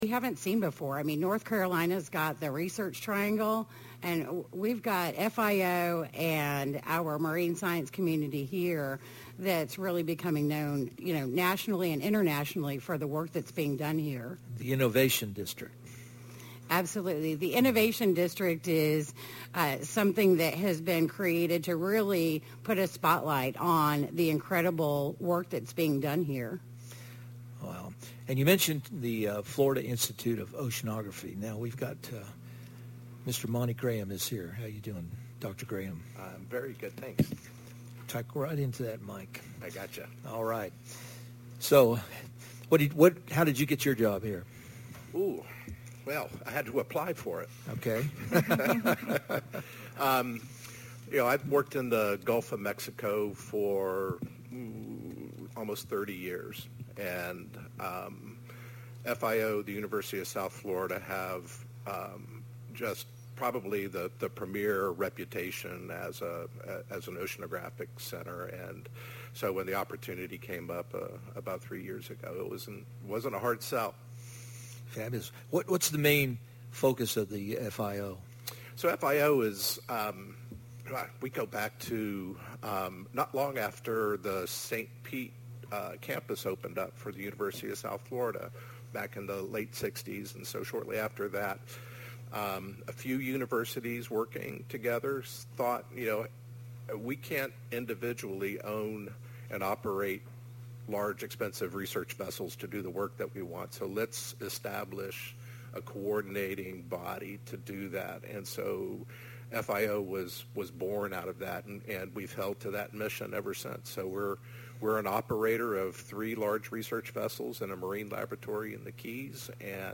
"Florida Folk Show" (Highlights) Live 4-6-24 on board the R/V Western Flyer